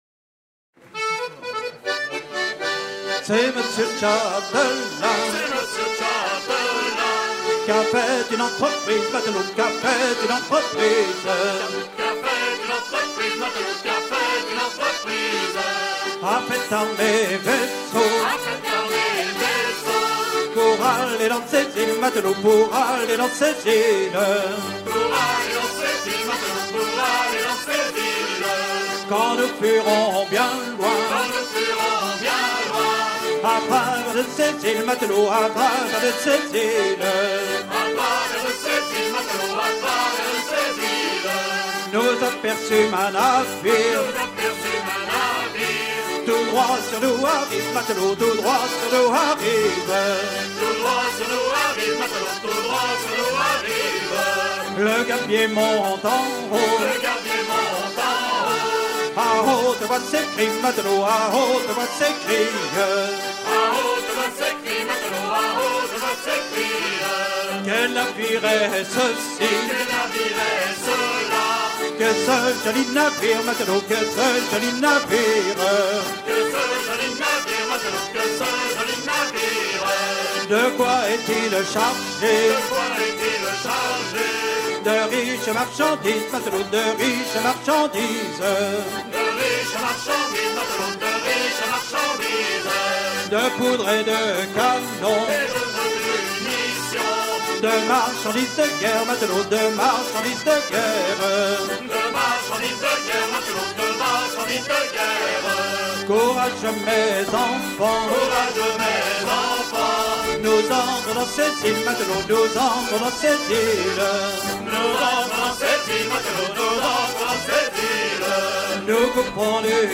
Version recueillie en 1978
danse : ronde à la mode de l'Epine
Genre laisse
Pièce musicale éditée